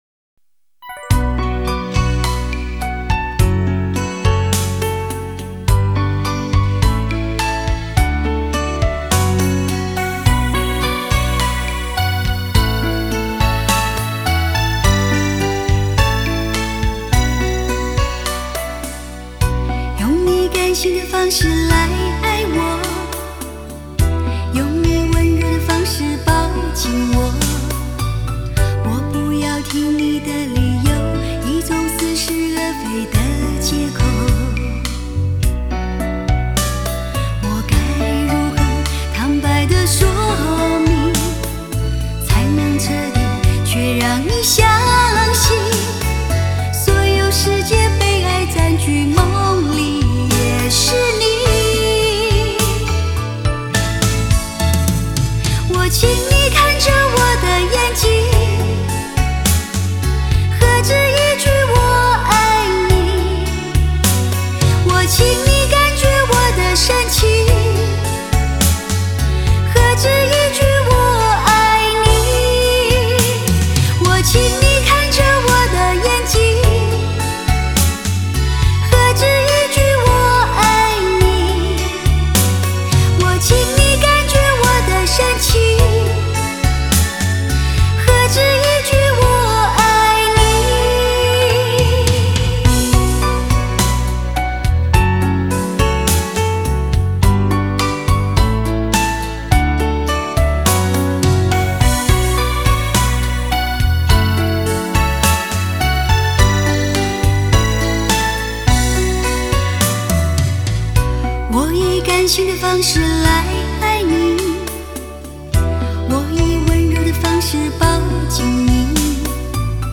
44.100 Hz;16 Bit;立体声